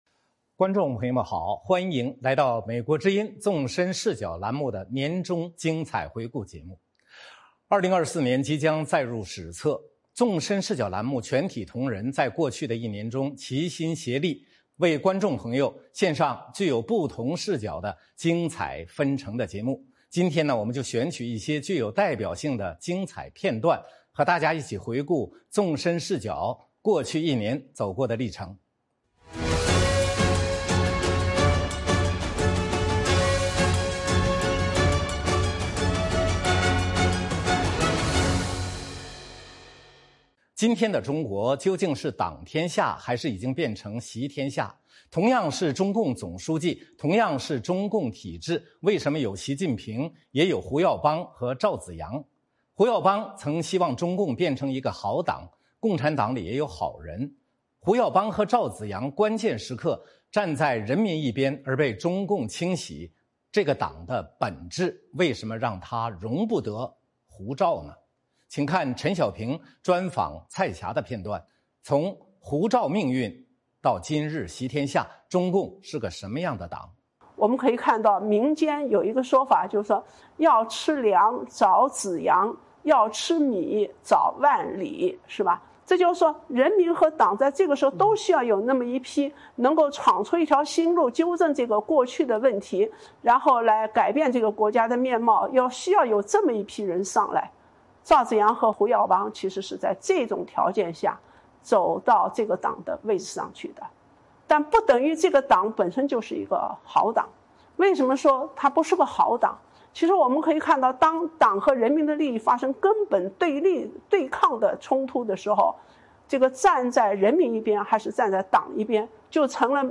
我们选取一些具有代表性的精彩片段，和大家一起回顾纵深视角过去一年走过的历程。 《纵深视角》节目进行一系列人物专访，受访者所发表的评论不代表美国之音的立场